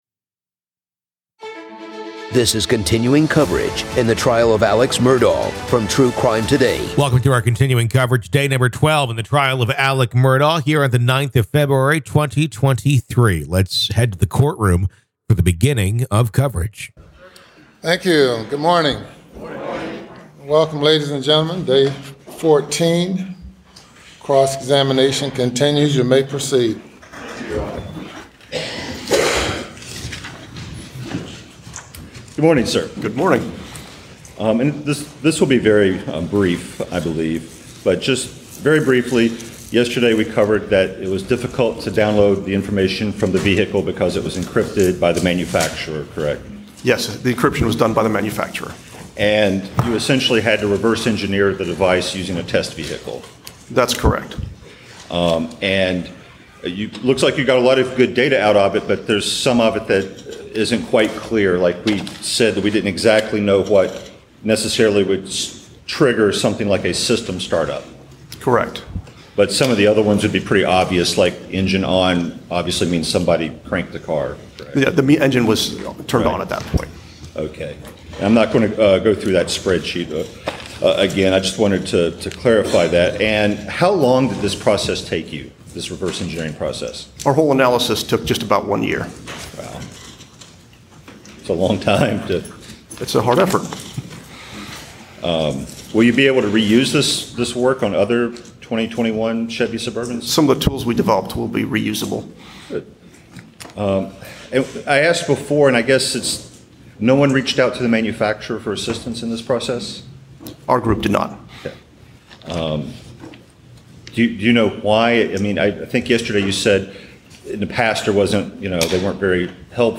This is our continuing coverage of the Alex Murdaugh murder trial. Listen to the entire trial, hour by hour on our podcast feed so you don’t miss a single moment of testimony and evidence being brought forth as Alex Murdaugh faces first-degree murder charges in the deaths of h...